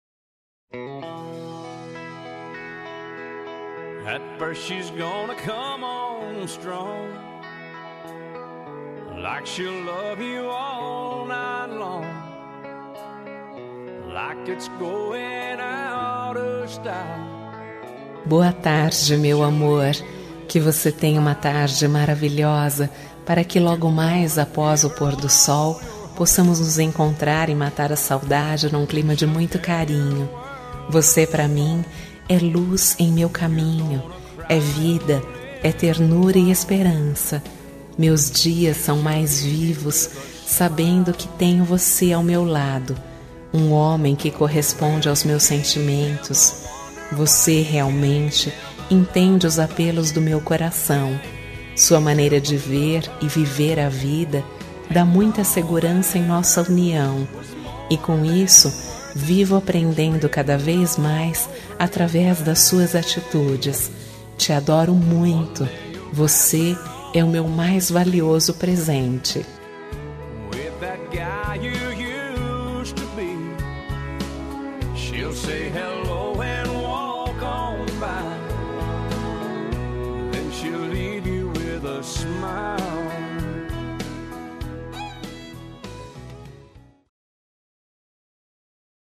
Telemensagem de Boa Tarde – Voz Feminina – Cód: 6319 – Romântica